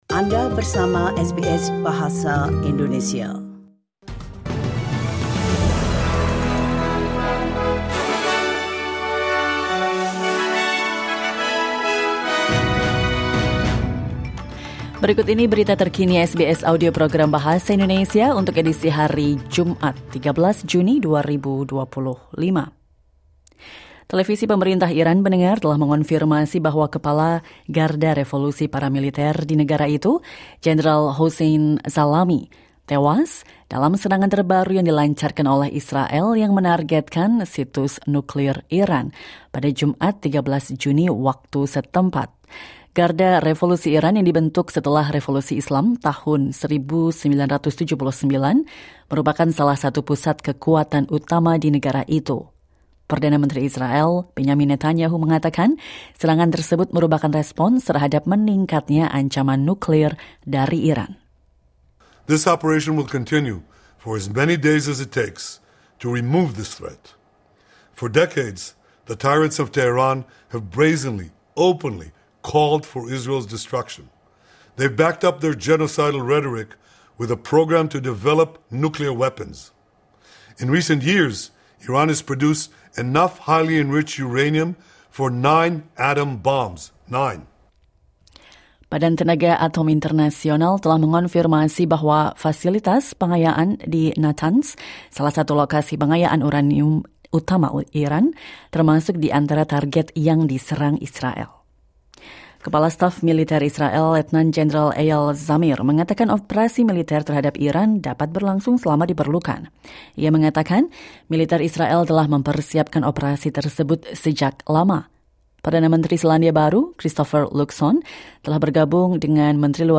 Latest News